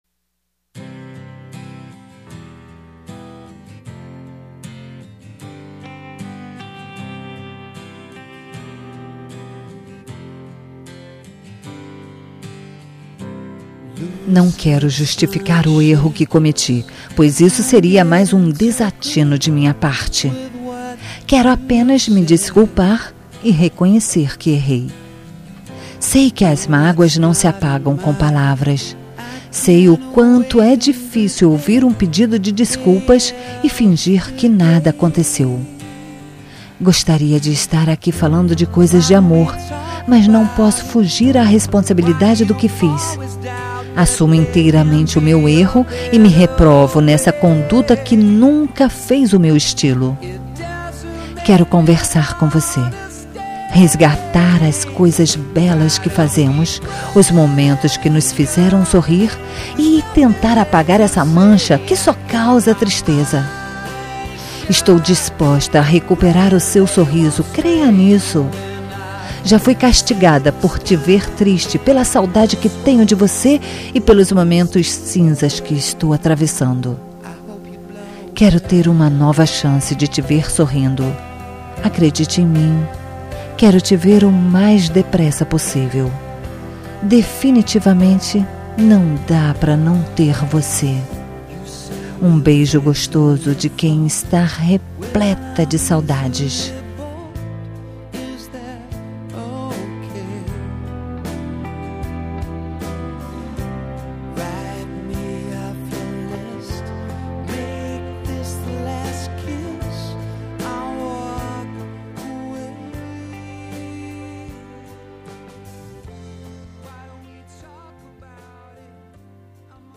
Telemensagem de Reconciliação – Voz Feminina – Cód: 7551